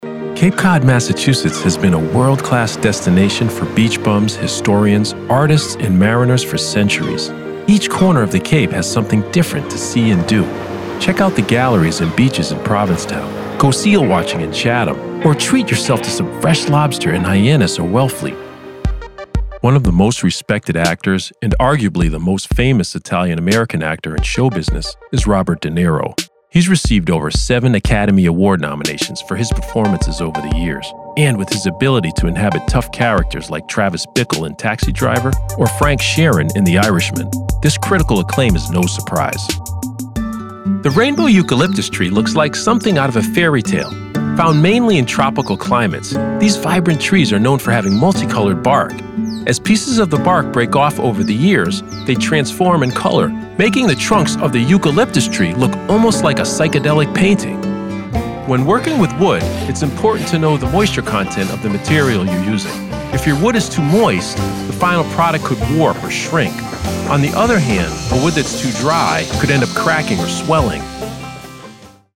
Narration demo
My voice is distinctive, rich and full of resonance, while sounding familiar.